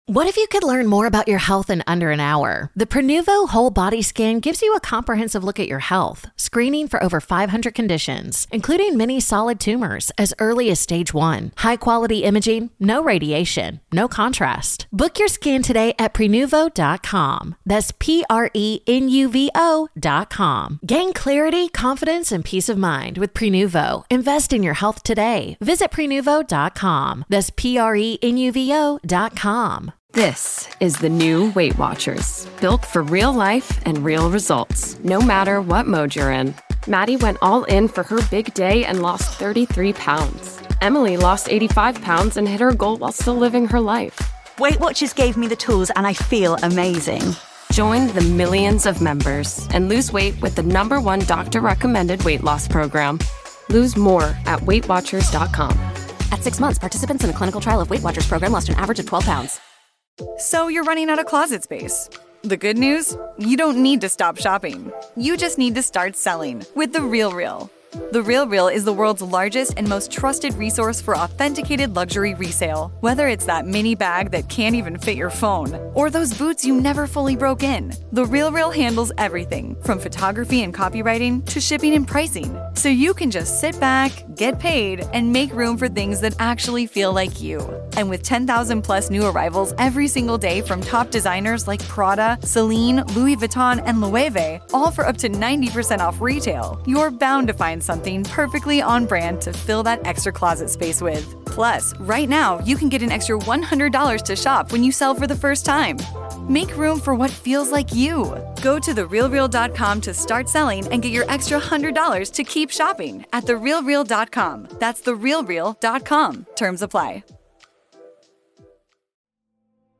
Each episode is a section of a classic Sherlock Holmes story, read in soothing tones and set to calming music to help you fall asleep.